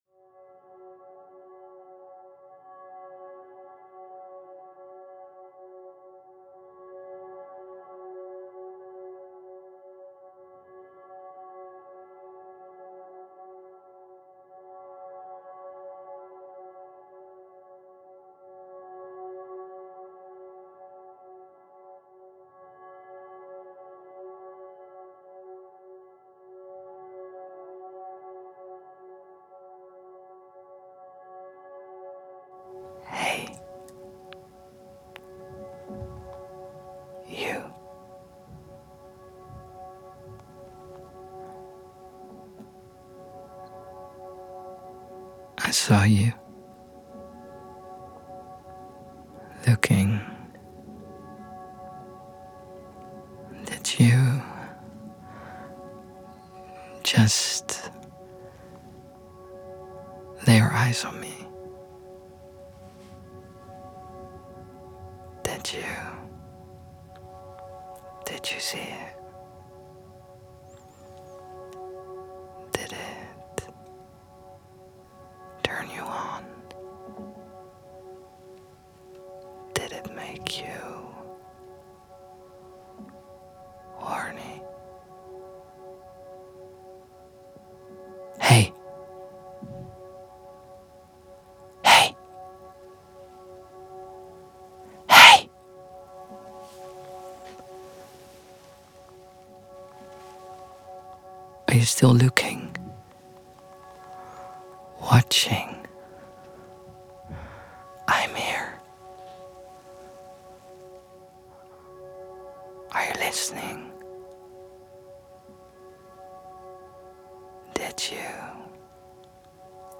2026 | Performance & sculptuur